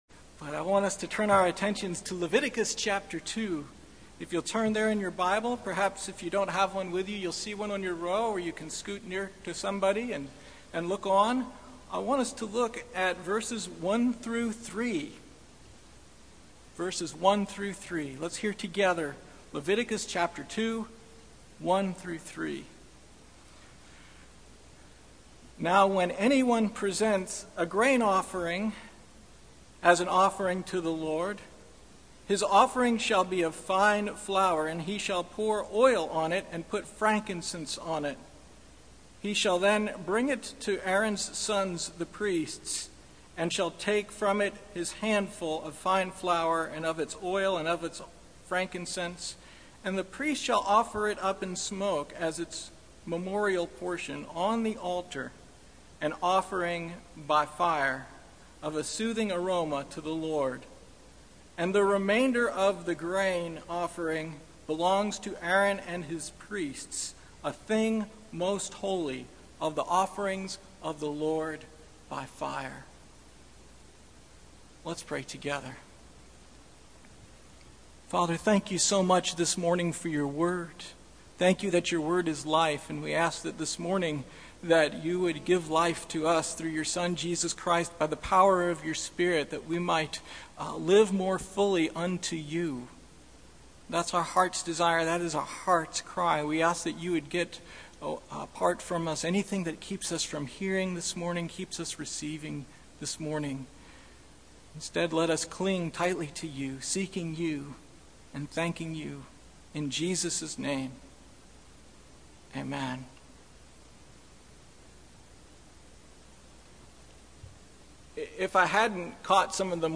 Passage: Leviticus 2:1-16 Service Type: Sunday Morning